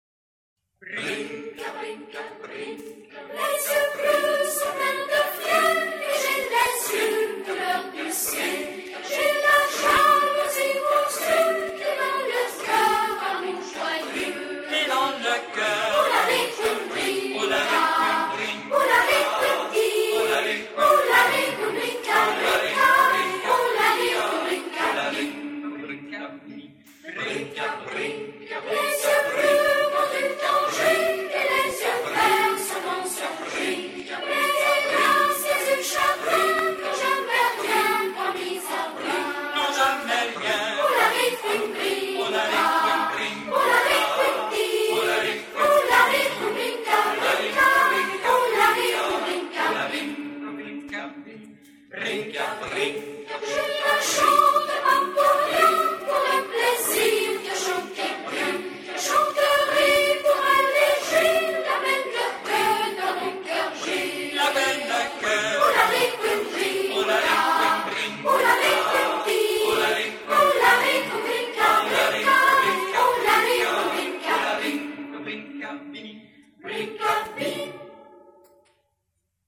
Folklore portugais – harm.